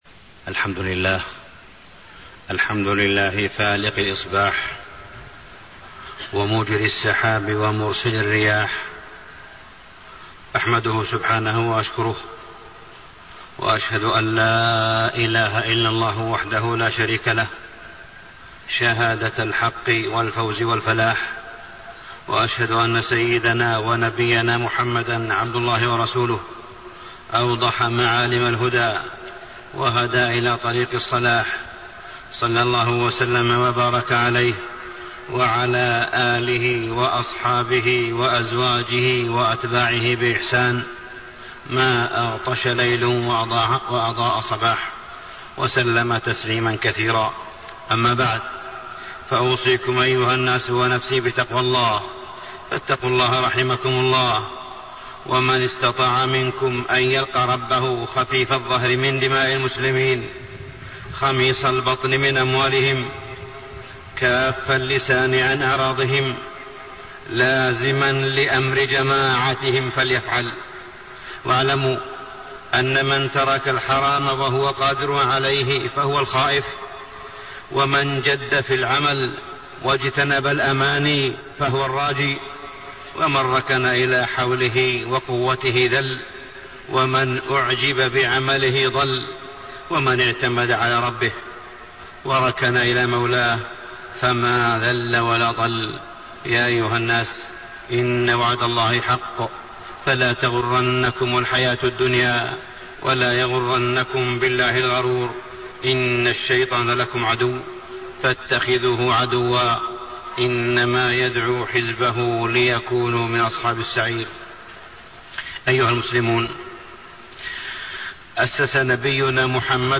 تاريخ النشر ١٠ جمادى الأولى ١٤٣٤ هـ المكان: المسجد الحرام الشيخ: معالي الشيخ أ.د. صالح بن عبدالله بن حميد معالي الشيخ أ.د. صالح بن عبدالله بن حميد وحدة الكلمة والحذر من الفرقة